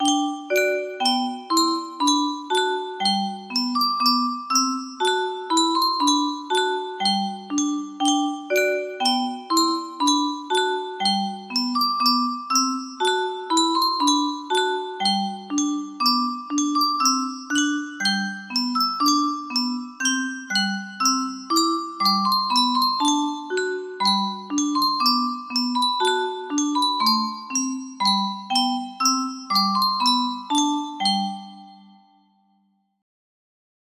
Full range 60
Jewish traditional melody for Chanukah